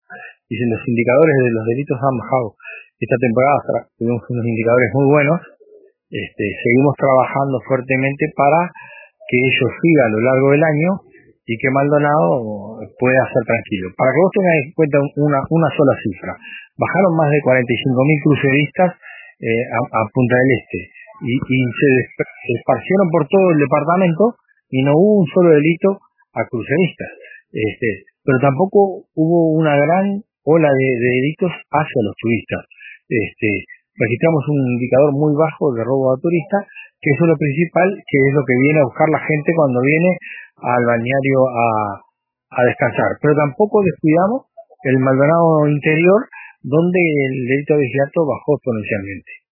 El jefe de Policía de Maldonado, Víctor Trezza, señaló a RADIO RBC que los indicadores delictivos en el departamento han mostrado una disminución durante la última temporada.